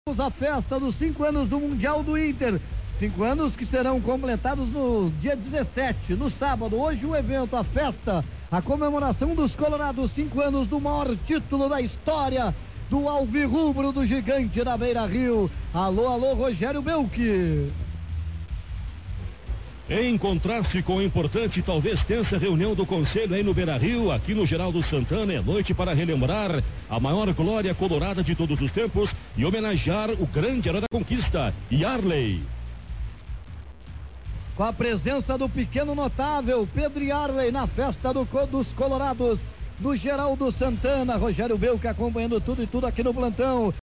Transmissão Rádio Guaíba Homenagem Iarley – parte 01